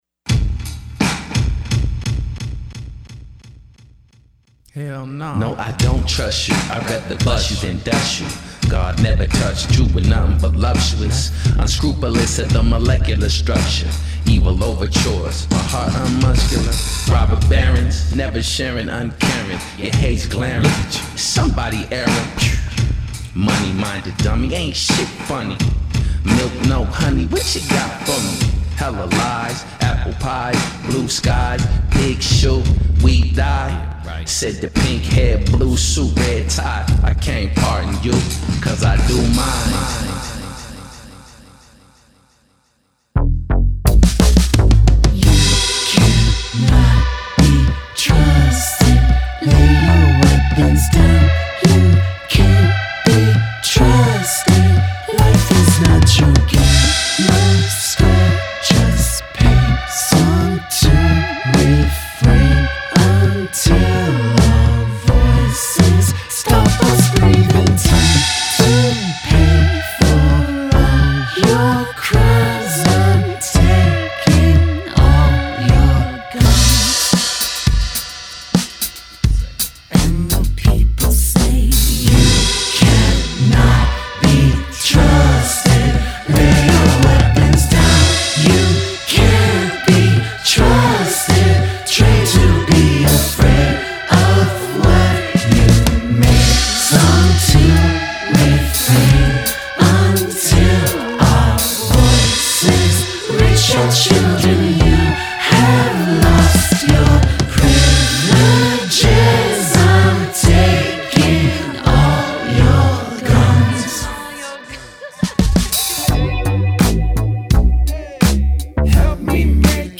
I’m taking all your guns" over a jazzy bassline.